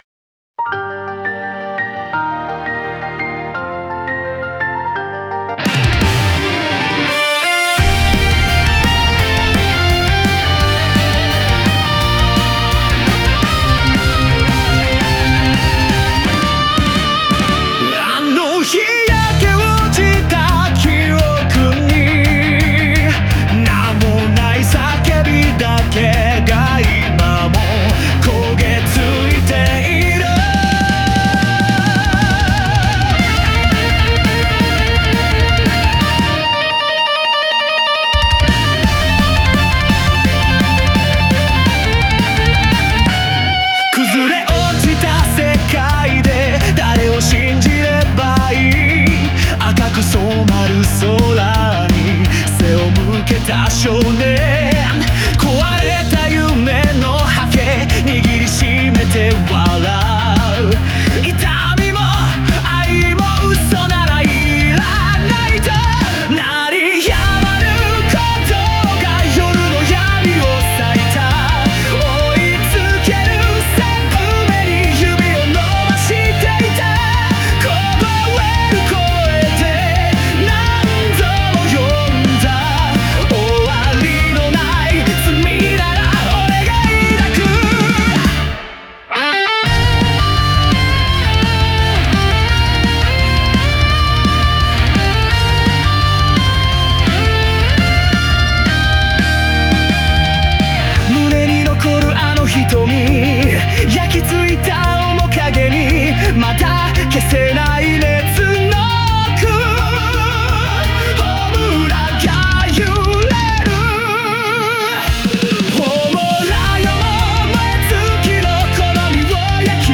静と動の激しいコントラスト、絶叫と囁きが交錯し、感情の振れ幅を増幅させる。